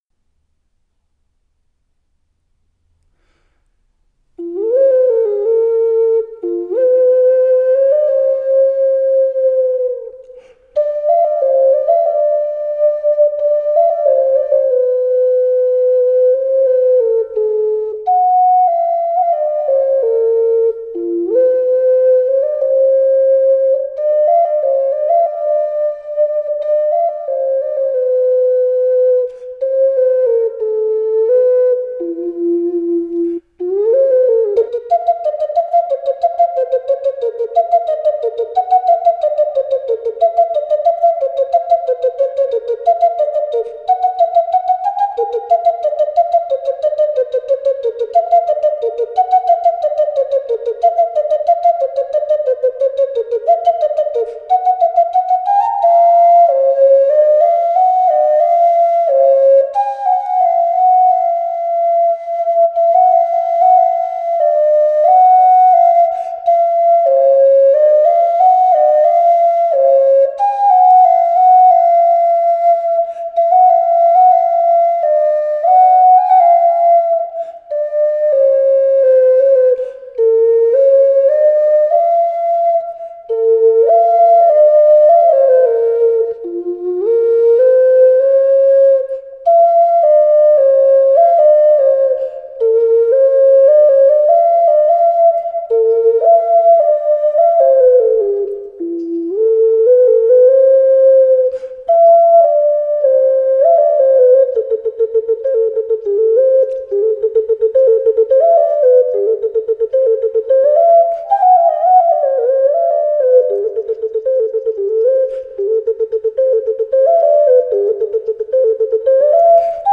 It creates a pure tone, with little color or timbre or distortion, etc. I know VERY little about recording, but I know that through headphones or a good sound system (like in my car), the mp3 sounds perfectly fine.
EDIT: Here's the ocarina-only track, as suggested, may be helpful to hear!